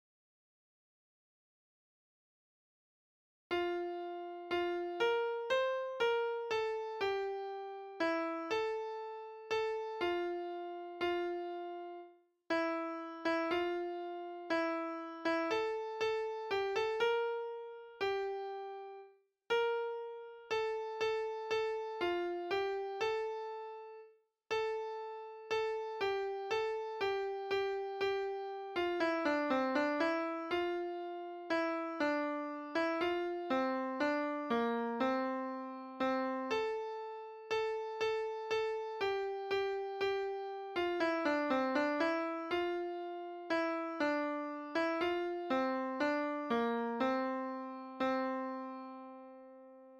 vierstemmig gemengd zangkoor
In bijlagen de gezongen versie van het lied, ook erbij de verschillende stemmen